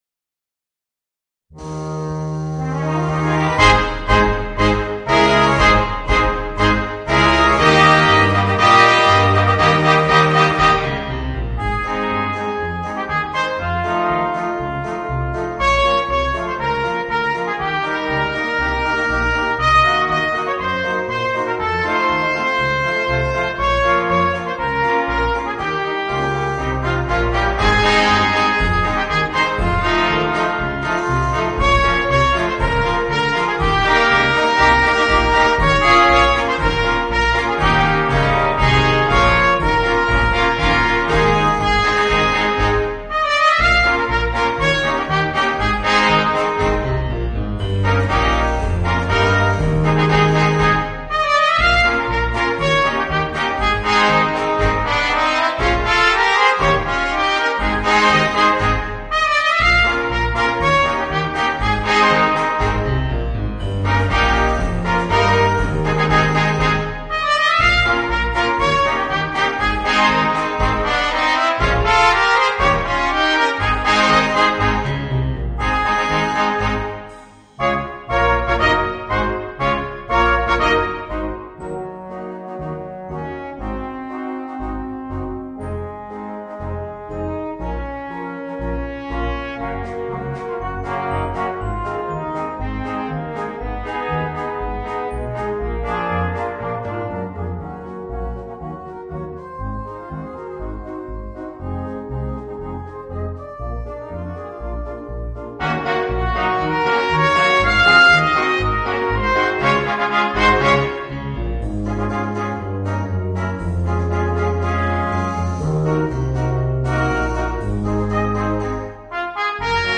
Voicing: 2 Trumpets and 3 Trombones